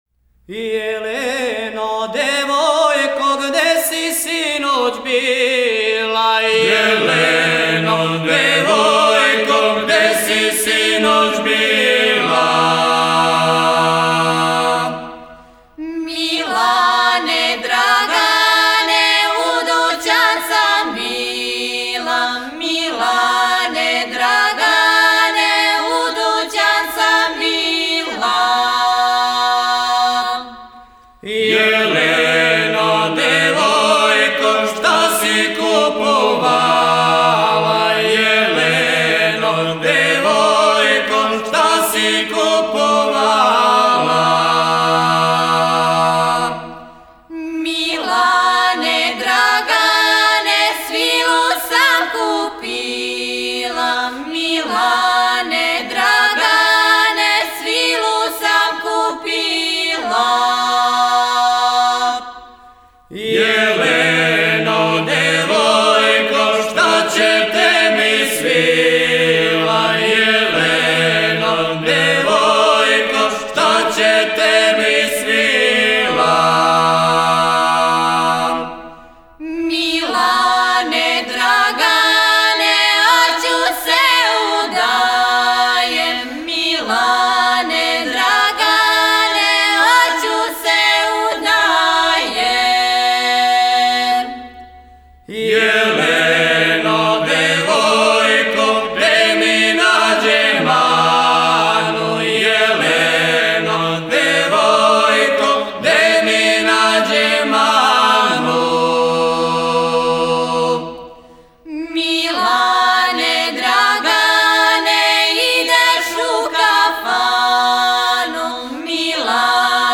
Порекло песме: Црноречје Начин певања: На бас Напомена: Љубавна песма